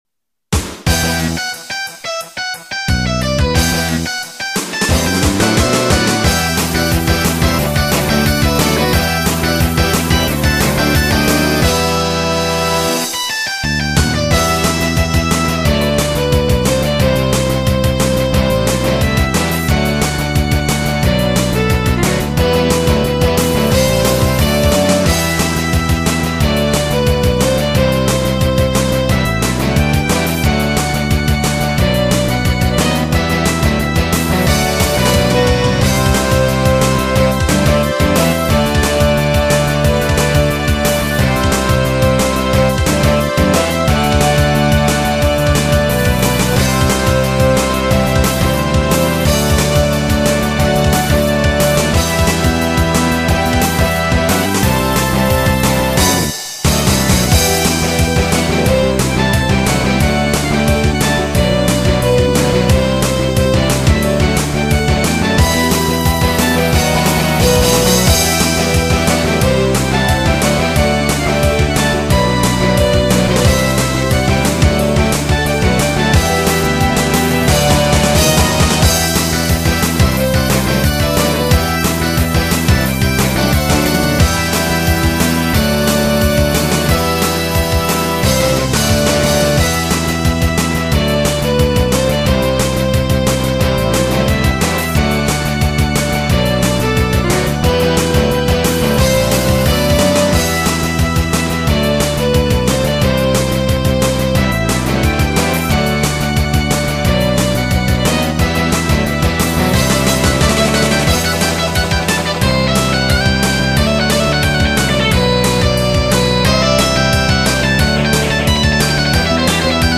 -Remix Version-   AC-XG MIDIFlick Rock